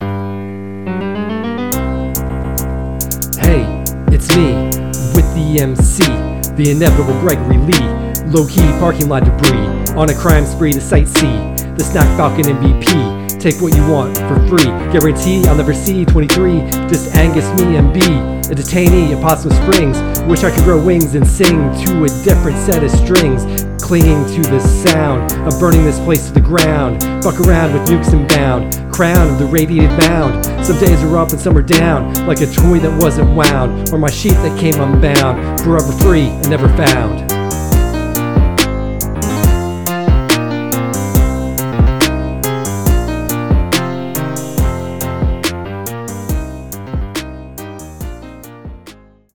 Rap from Episode 77: Night in the Woods – Press any Button
Night-in-the-woods-rap.mp3